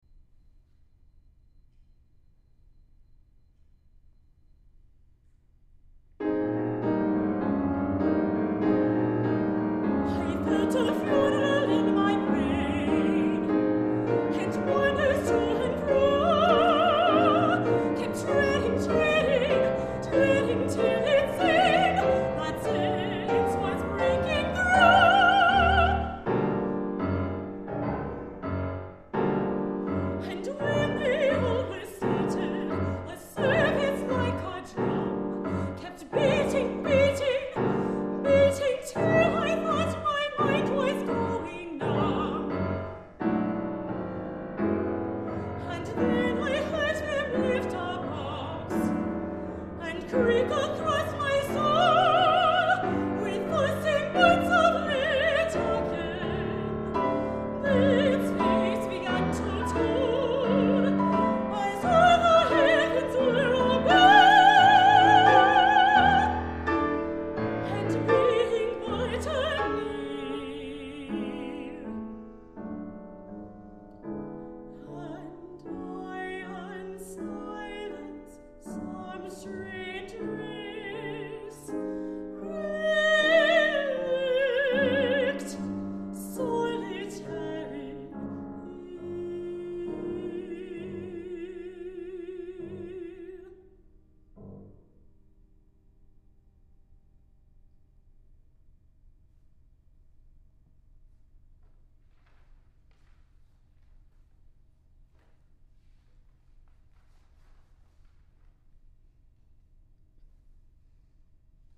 Mezzo-Soprano
Junior Recital